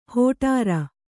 ♪ hōṭāra